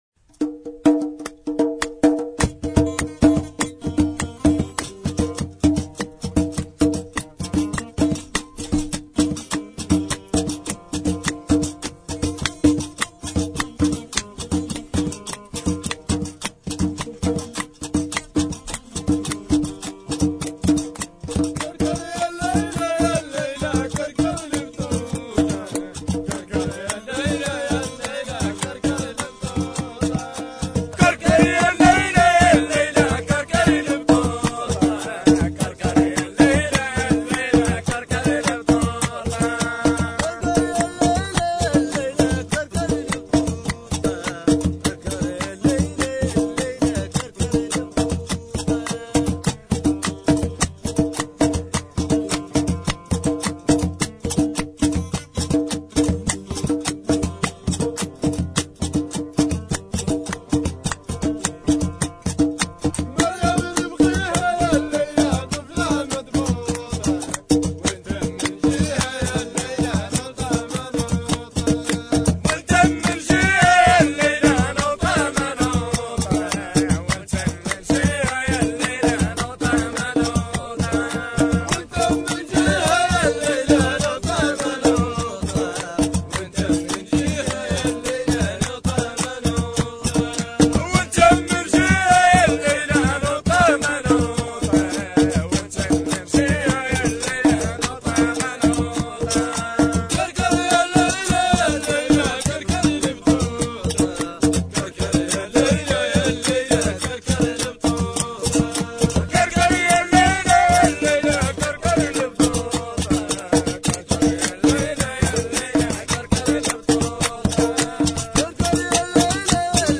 Chants traditionnels sahraouis
4 - Rythme " TBAL " ou GUITARE